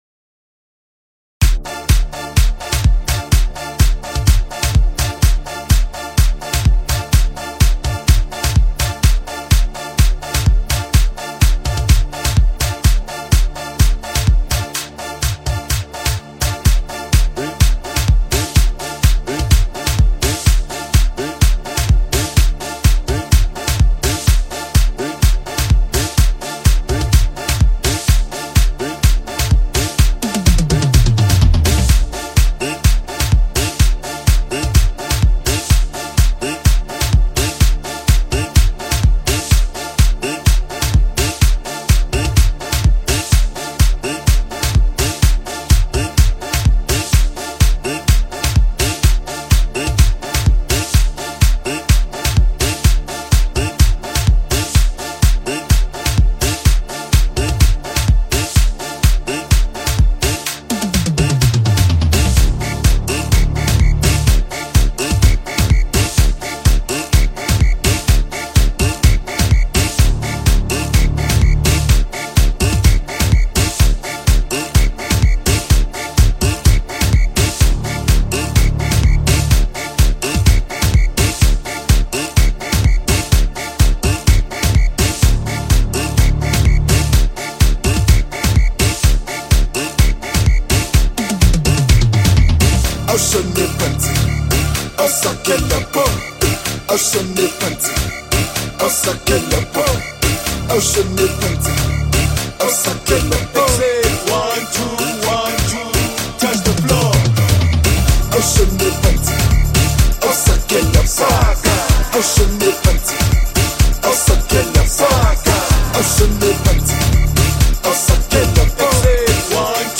Gqom